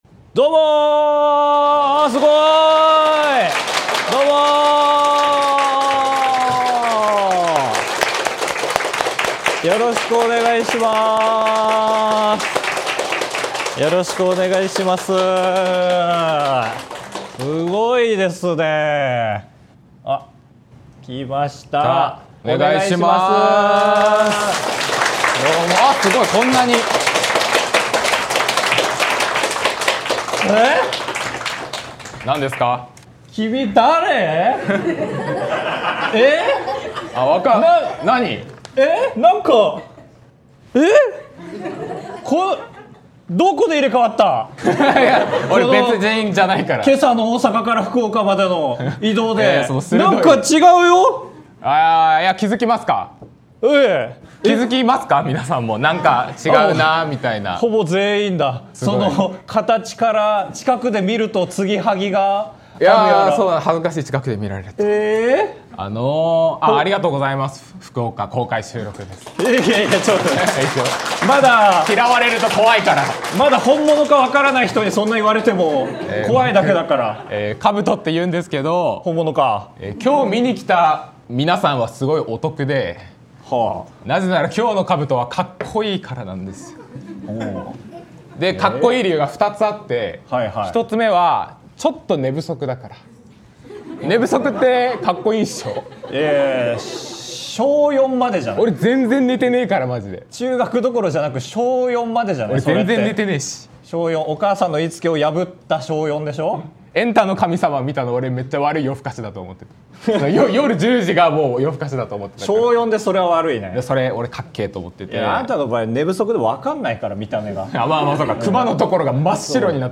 ▽3日連続で公開収録すると起きるバグ ▼なんしよーと？